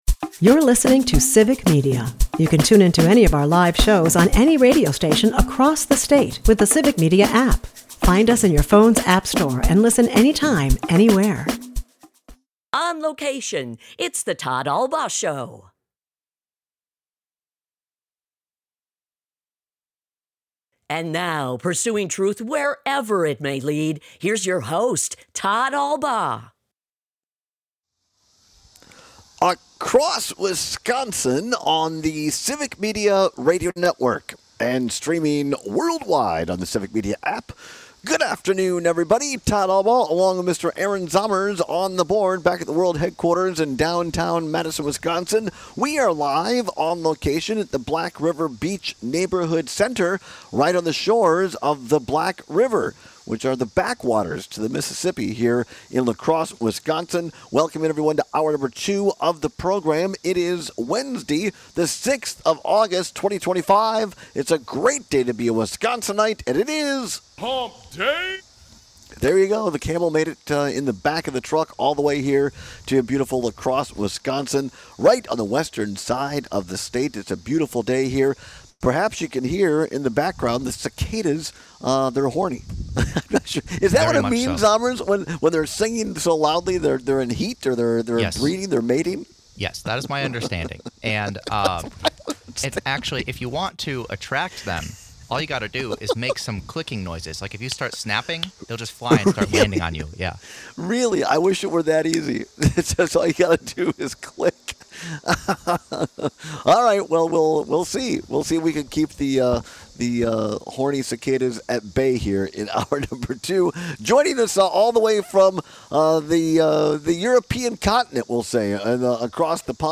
airs live Monday through Friday from 2-4 pm across Wisconsin.